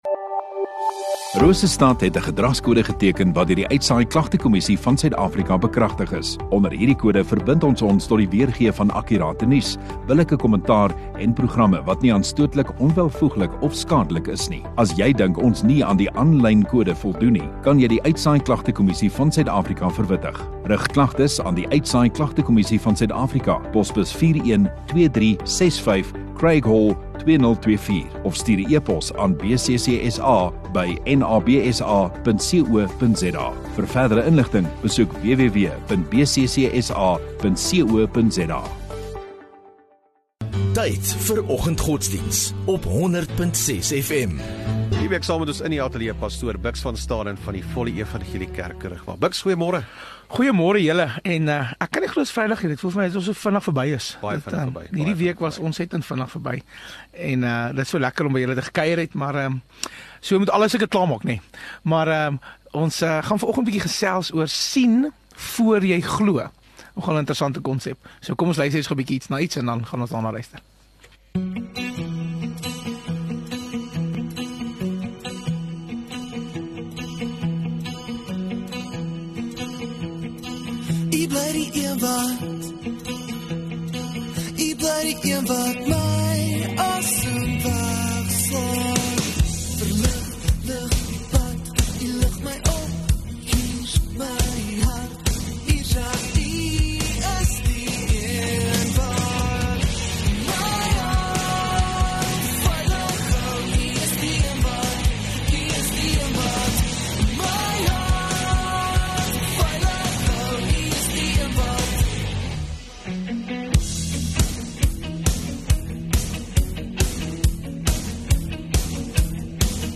View Promo Continue Install Rosestad Godsdiens 11 Oct Vrydag Oggenddiens